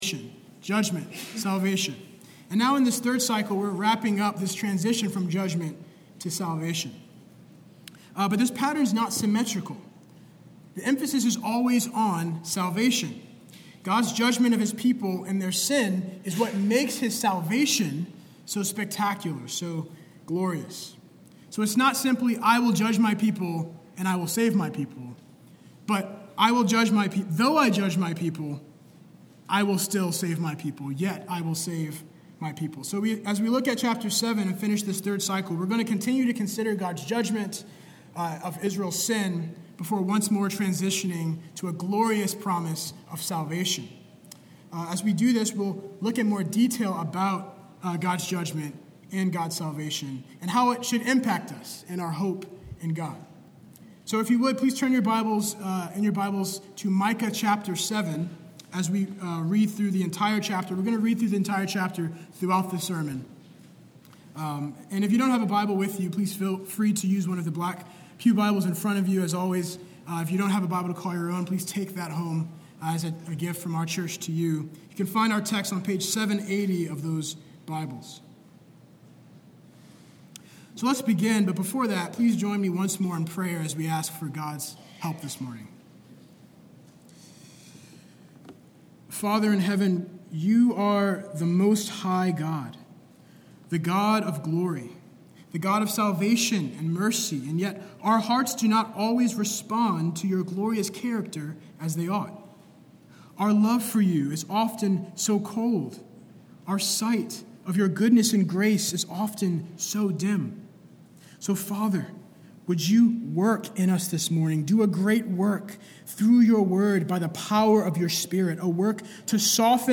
Sermon-105.mp3